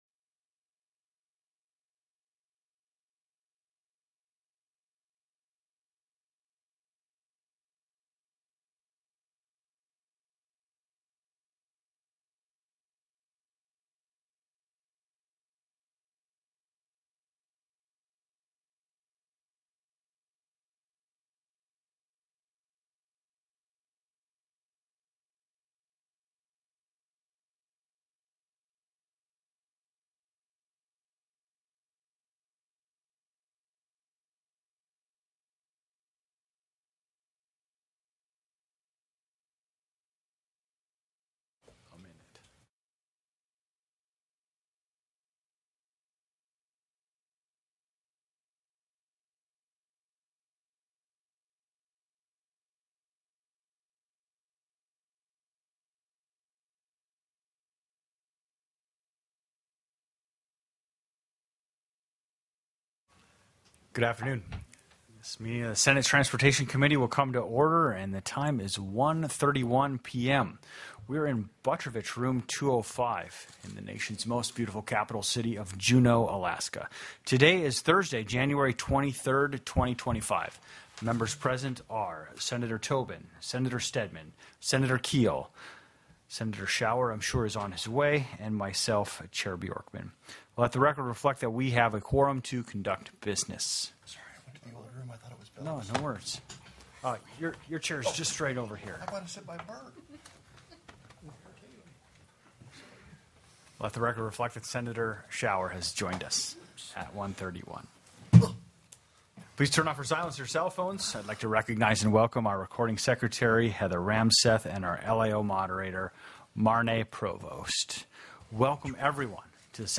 01/23/2025 01:30 PM Senate TRANSPORTATION
The audio recordings are captured by our records offices as the official record of the meeting and will have more accurate timestamps.